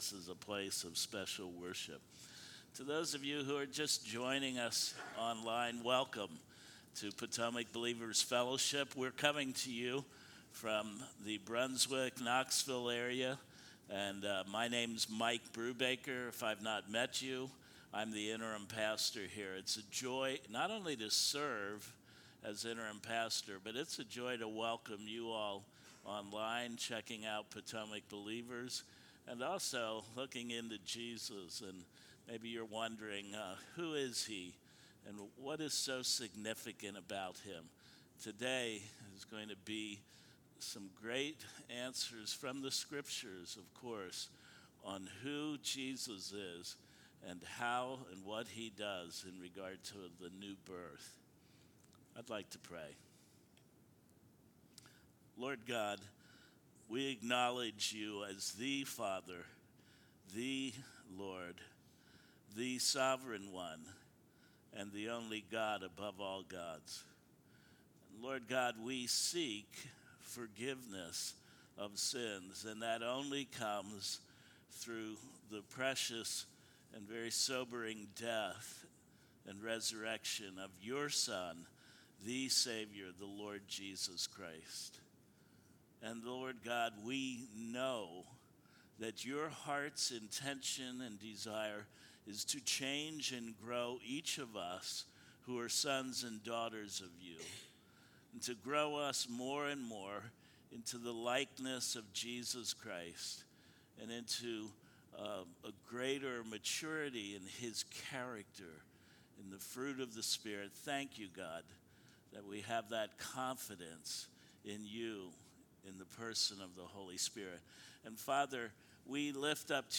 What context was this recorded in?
Passage: John 3:1-14,30-36 Service Type: Sunday Morning Worship